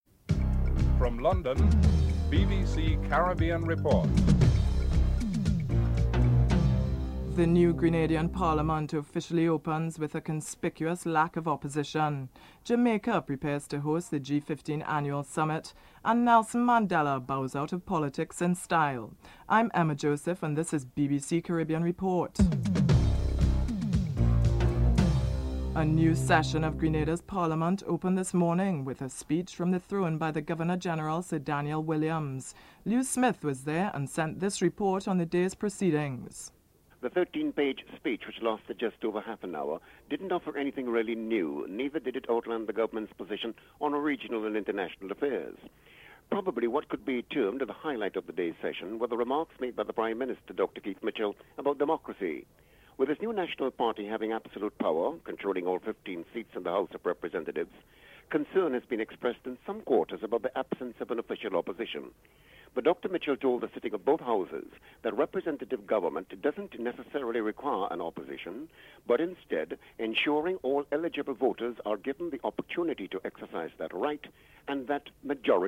Jeremy Vine reports on the formal opening ceremony where Mr. Mandela staunchly defended his government efforts to get power, water and phone lines to poorer areas, reduce crime and oppose implementing the death penalty (10:16 - 12:16)